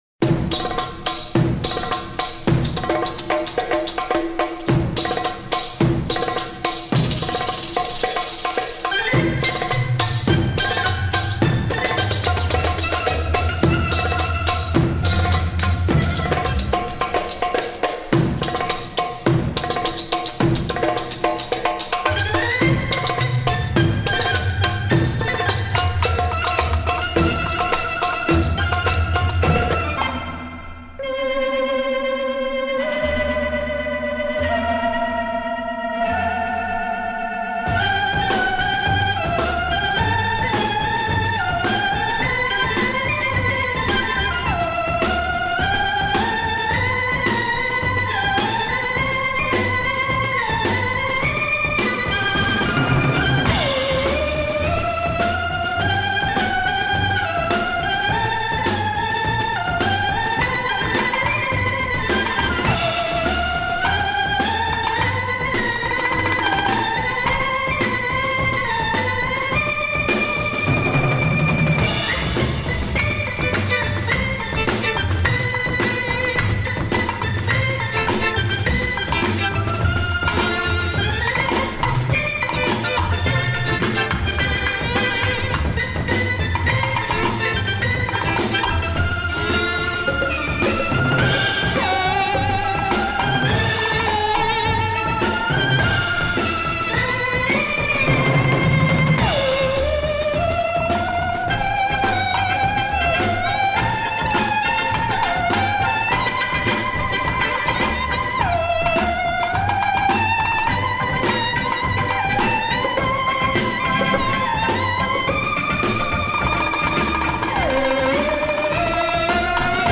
Organ psych groove from Egypt by this blind musician !